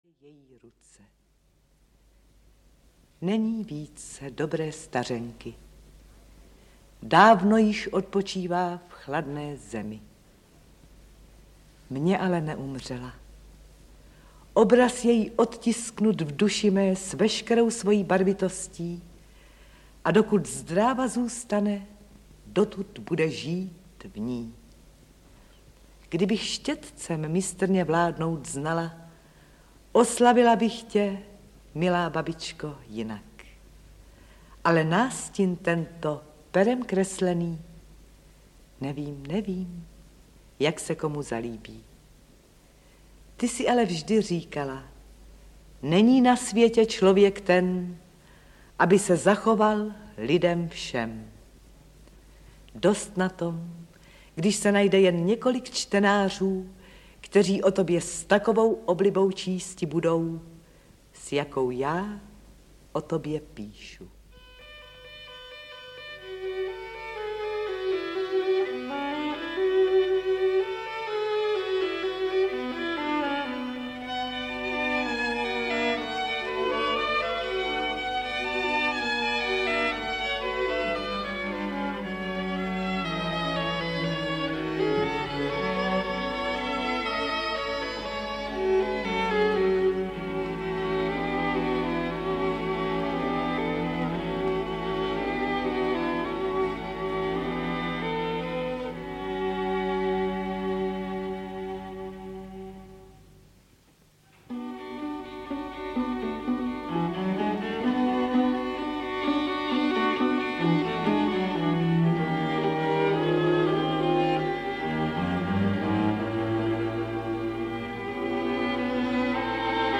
Ukázka z knihy
• InterpretVlasta Fabianová, Růžena Nasková